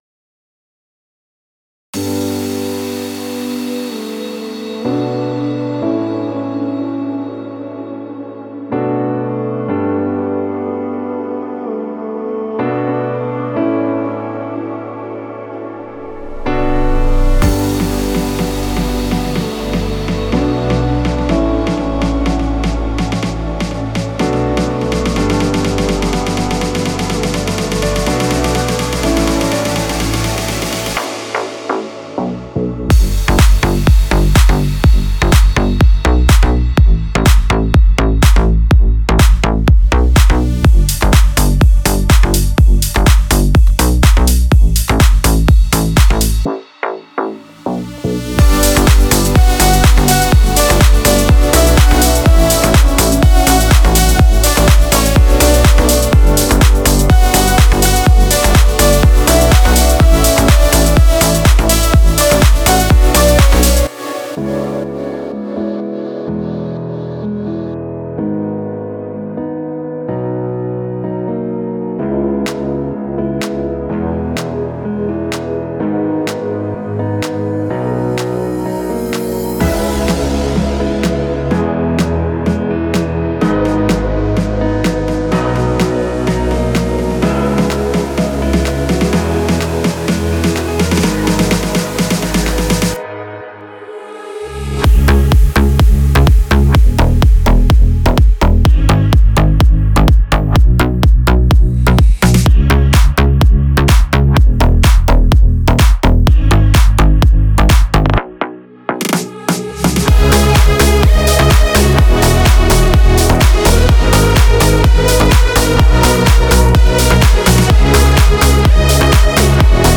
Genre:House
タイトな低域のエネルギーとキャッチーなメロディックフックでクラブ対応のバンガーを制作したいプロデューサーに最適です。
デモサウンドはコチラ↓